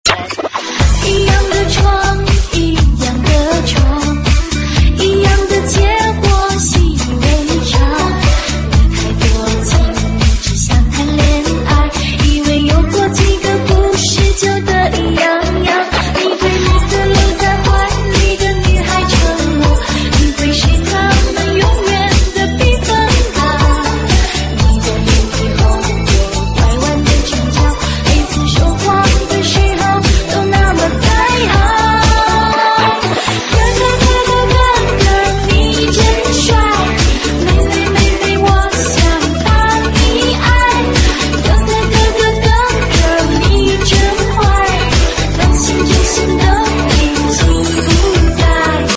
DJ舞曲